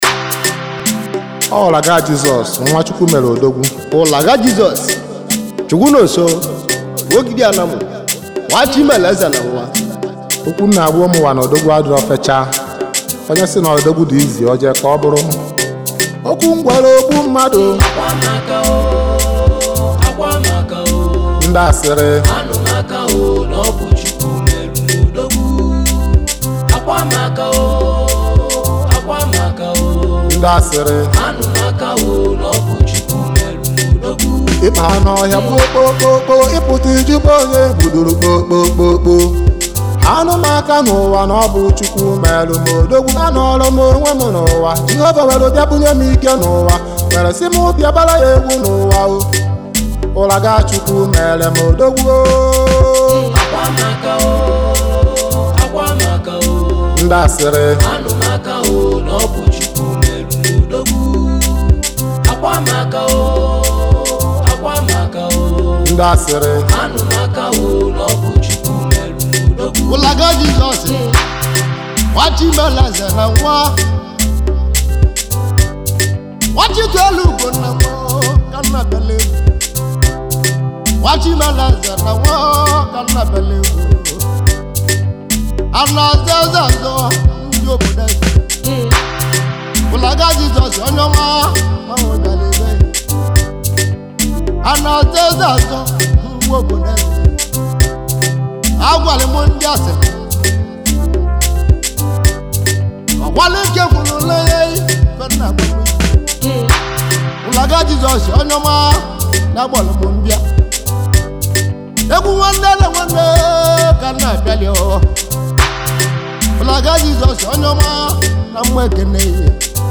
• Genre: Afro-Gospel / Inspirational
• Vibe: Worshipful, Energetic, Spirit-lifting